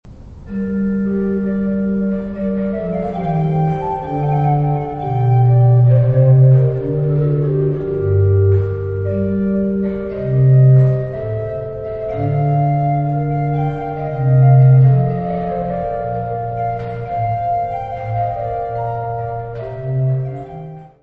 : stereo; 12 cm
Orgão histórico da Abbaye de Saint-Michel en Thiérache
orgão
Music Category/Genre:  Classical Music